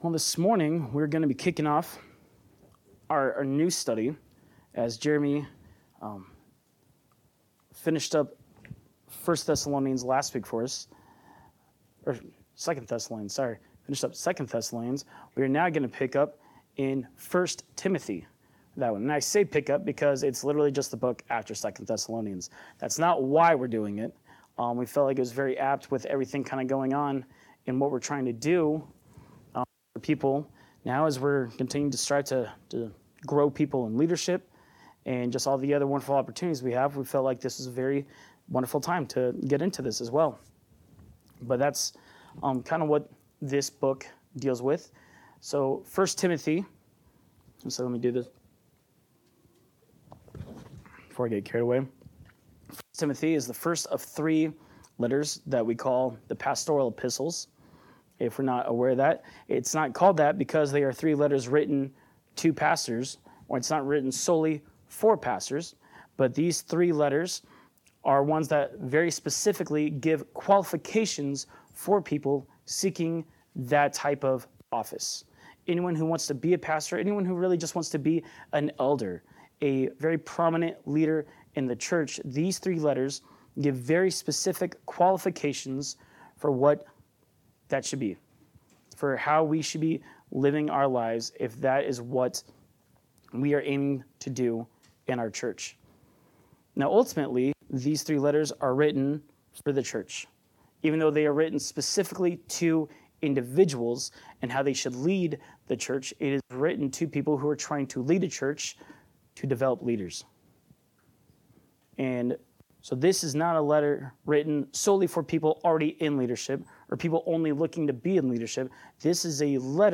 Sermon-7_20_25.mp3